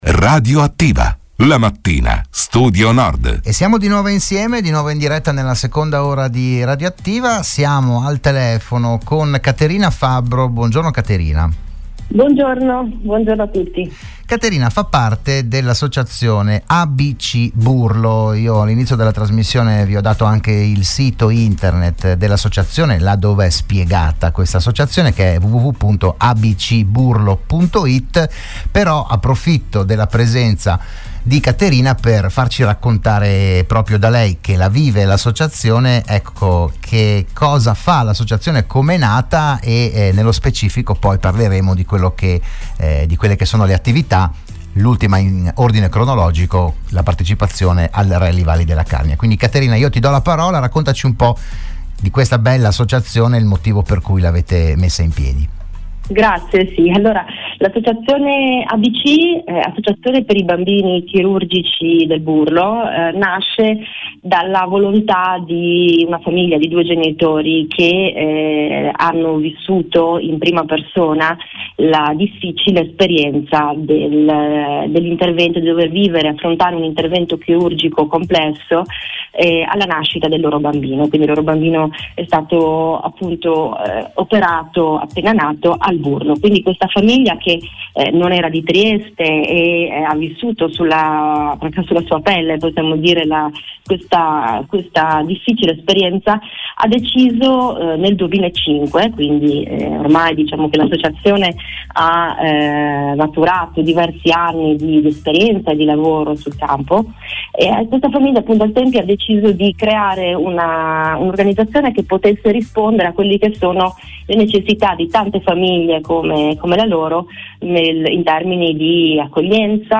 RadioAttiva “, la trasmissione di Radio Studio Nord